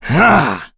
Dakaris-Vox_Attack3.wav